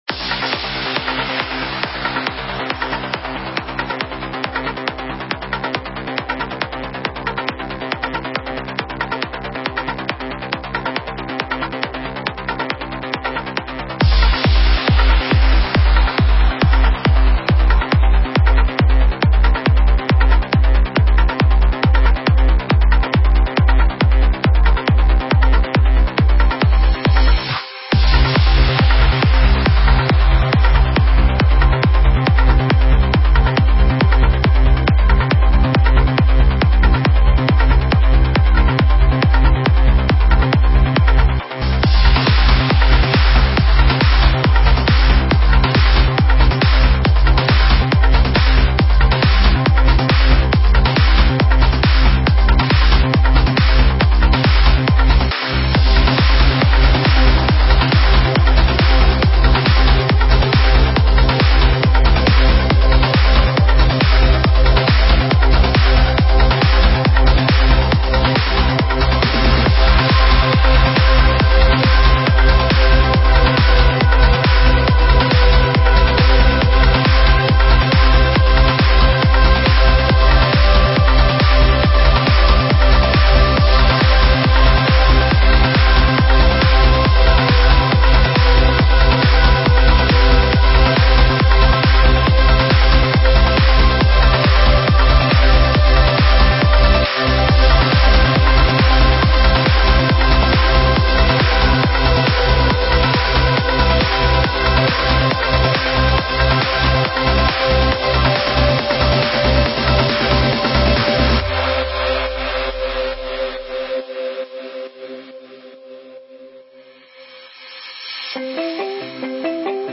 Стиль: Trance